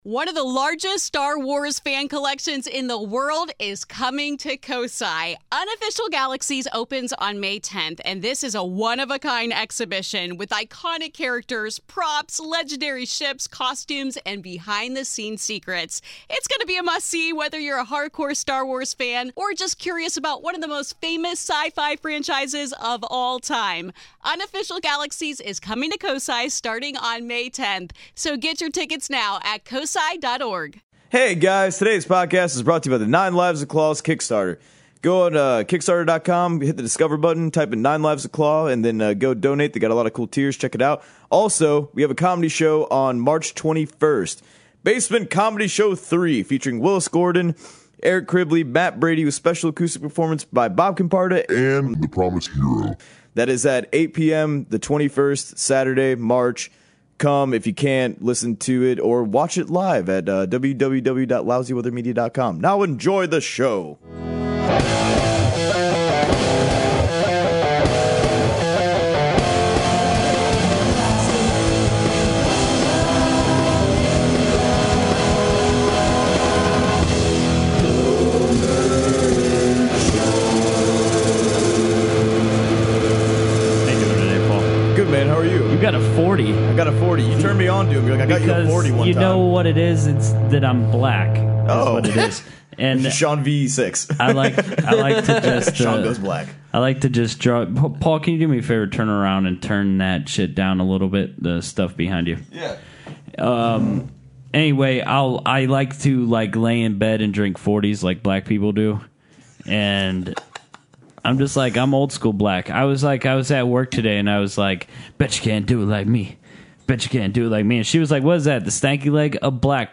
Lady energy in the BOOTH!